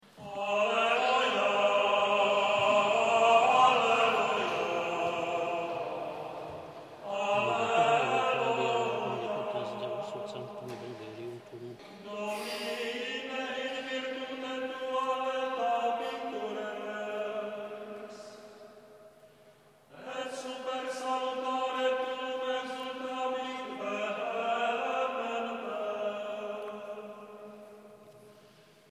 Gregoriánský chorál
10. neděle v mezidobí